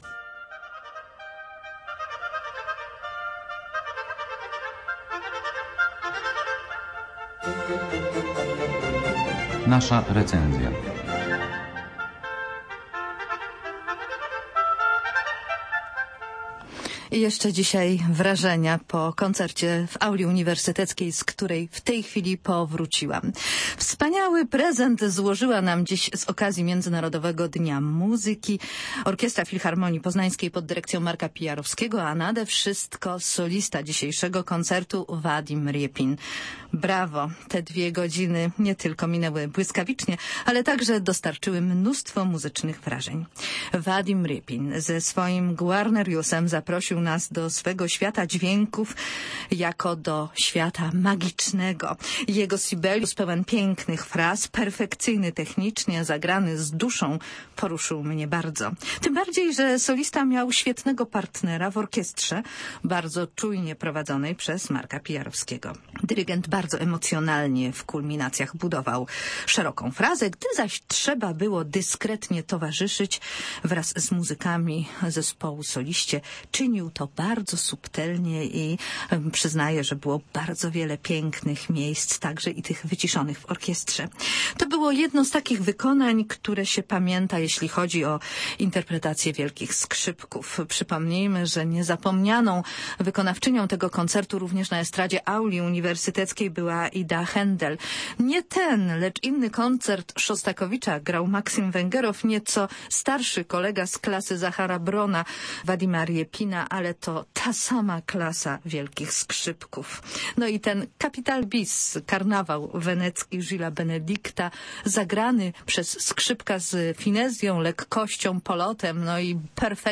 cp1d2nadsjdr849_recenzja_vadim_repin.mp3